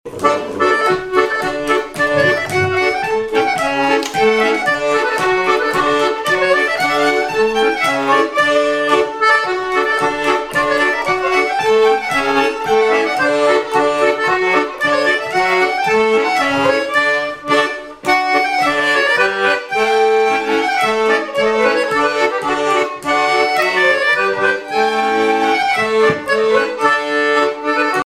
danse : quadrille : pastourelle
Répertoire de bal au violon et accordéon
Pièce musicale inédite